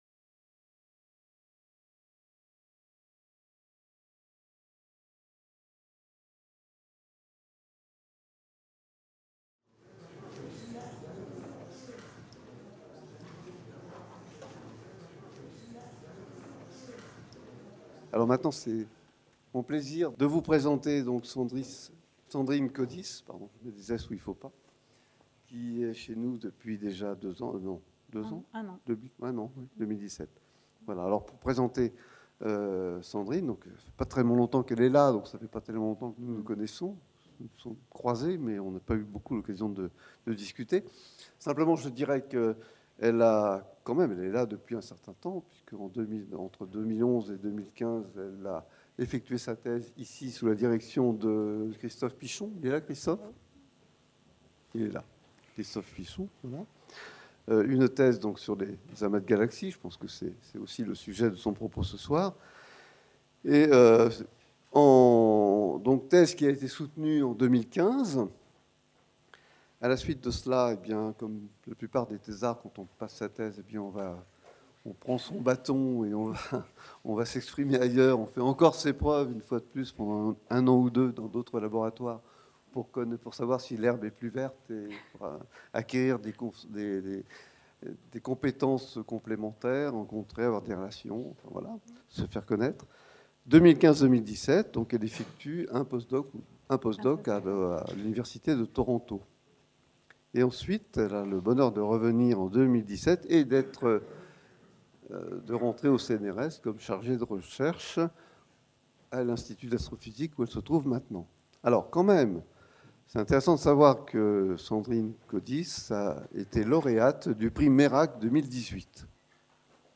Elles forment un véritable réseau en bulles de savon souvent appelé toile cosmique et composé de vides, de murs, de filaments et de noeuds dans lesquels les amas de galaxies résident. C'est un voyage au sein de la fabrique cosmique qui est proposé à l'occasion de cette conférence.